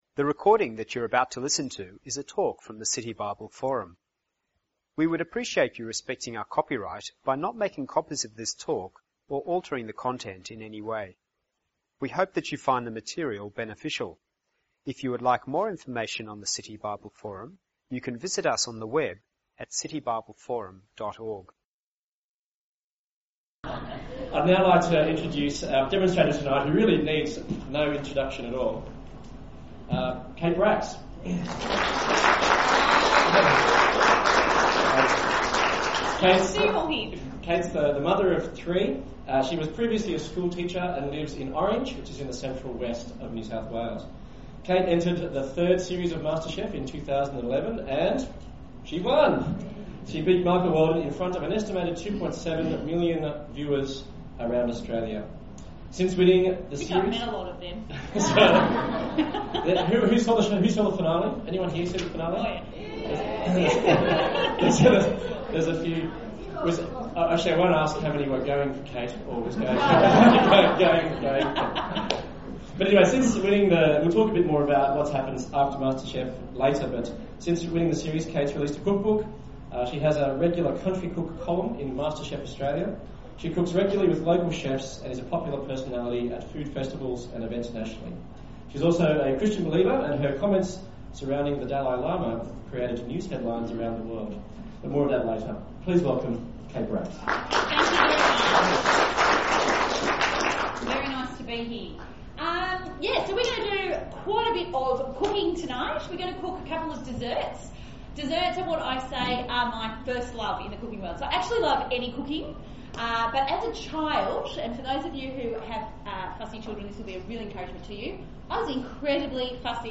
interviews Kate Bracks